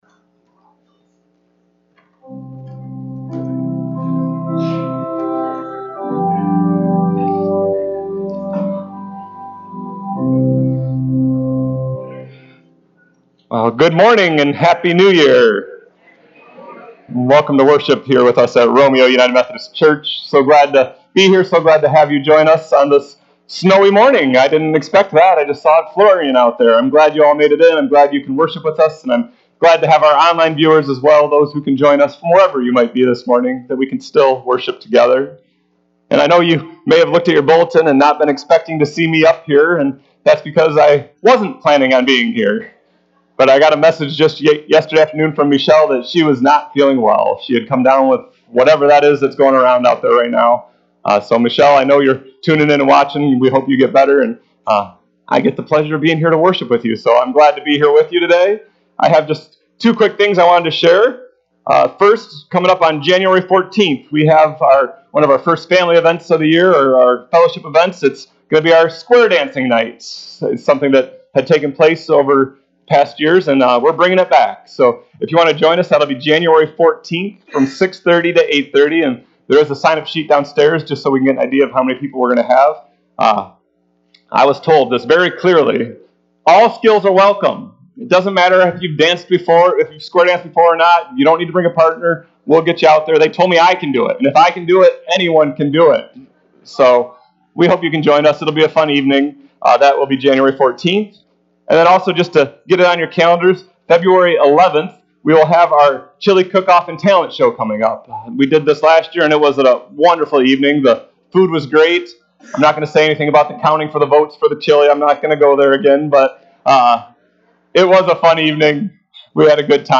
Worship Service January 1, 2023